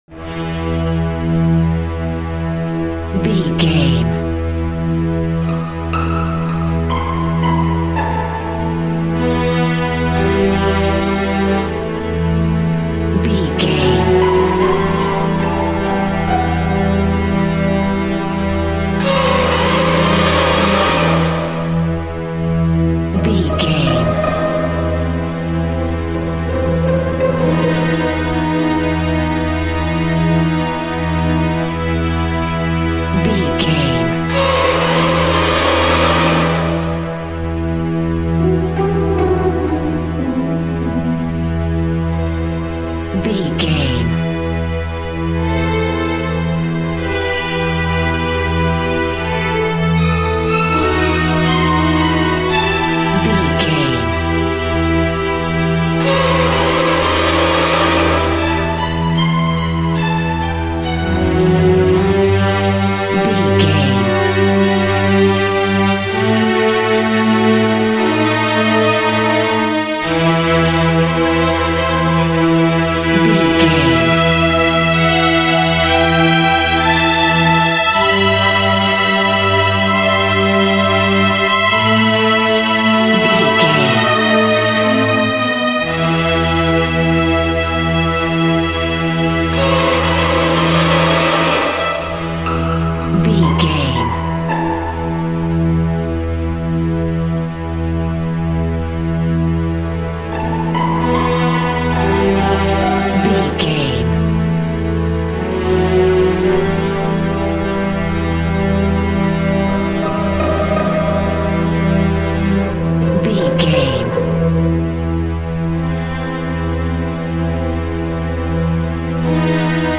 Aeolian/Minor
scary
ominous
dark
suspense
eerie
strings
synthesizer
Synth Pads
atmospheres